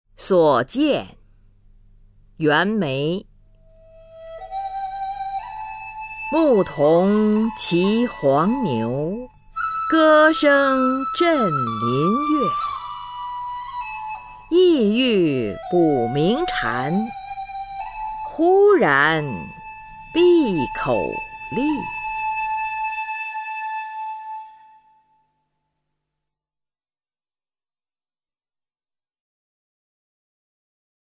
[清代诗词诵读]袁枚-所见 配乐诗朗诵